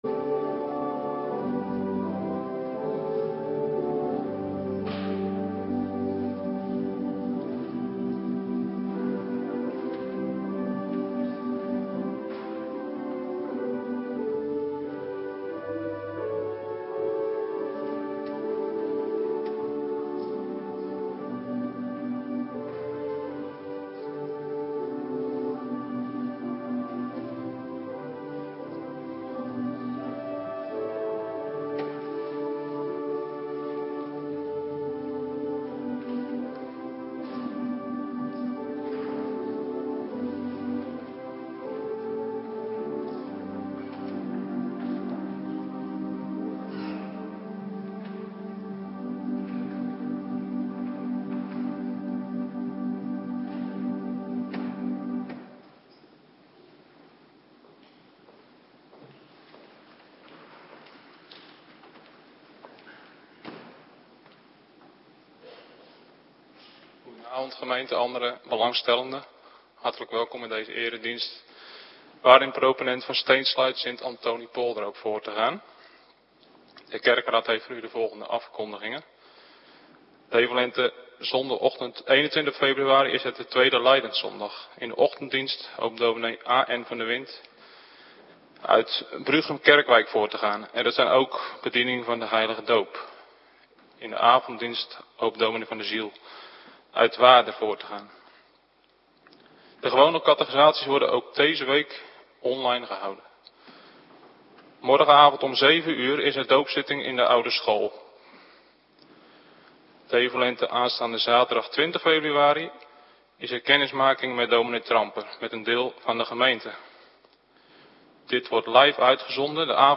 Avonddienst - Cluster 1
Locatie: Hervormde Gemeente Waarder